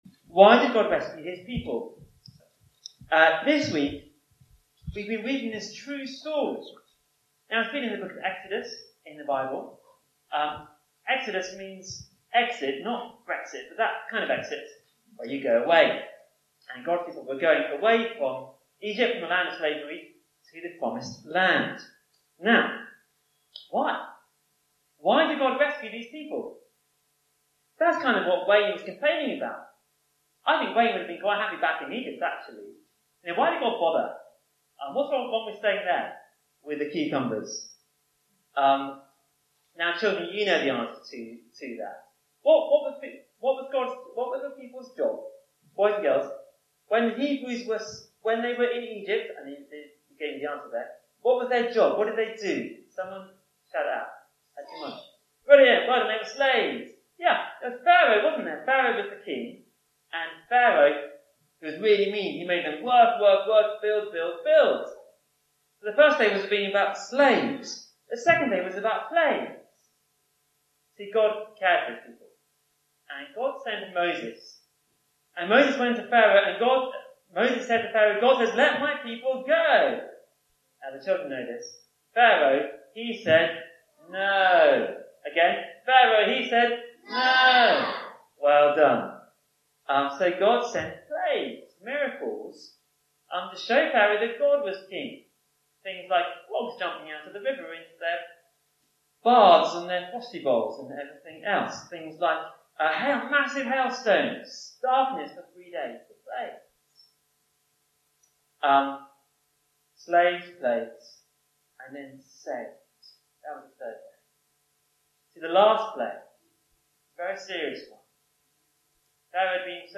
Download or listen online to interesting talks answering life’s big questions and showing what being in a relationship with God and following the Bible is really all about...